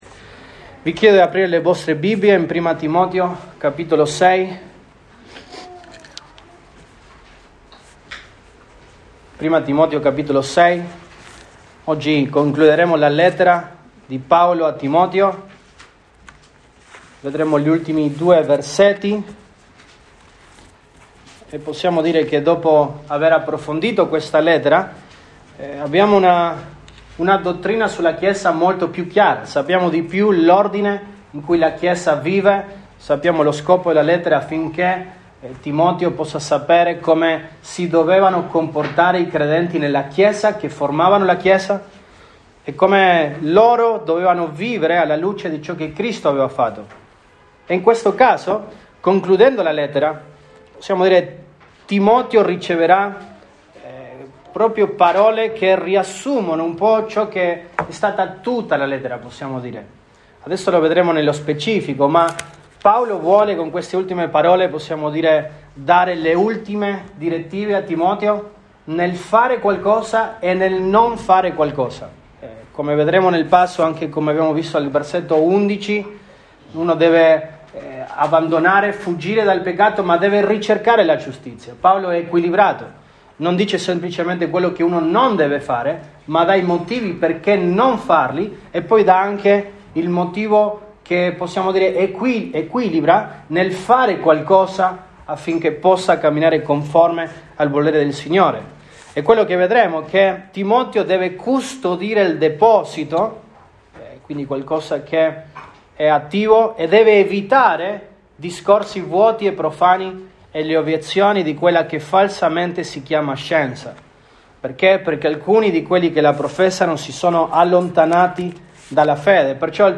Mar 06, 2022 Custodire la verità ed evitare l’errore MP3 Note Sermoni in questa serie Custodire la verità ed evitare l’errore.